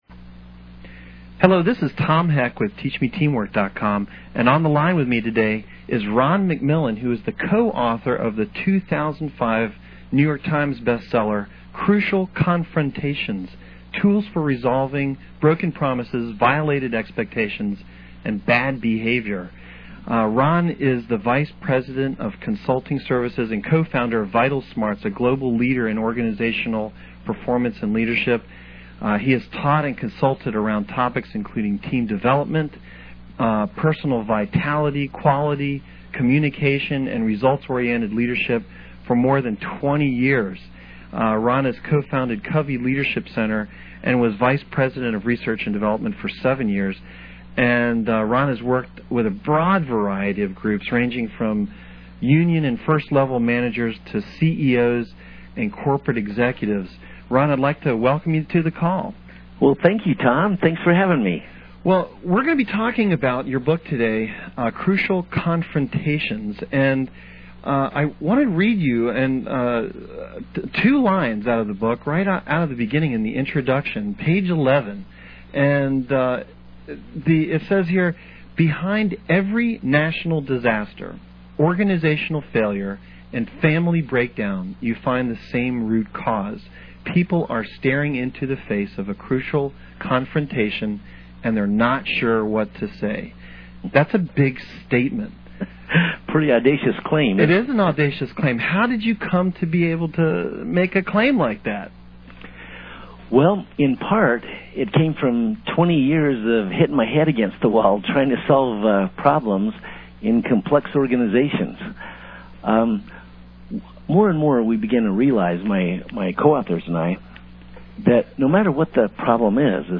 Click the link below to listen to the 50 minute audio interview in mp3 format: